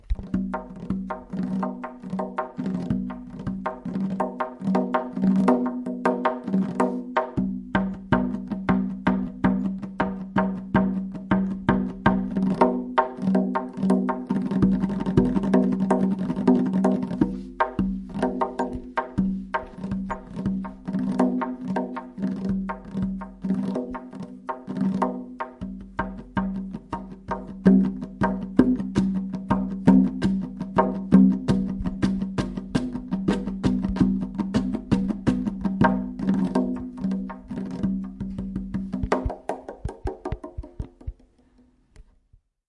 描述：声音记录在“巴塞罗那跨文化之子”研讨会的框架内。
Tag: tombak 波斯 乐器 打击乐器 elsodelescultures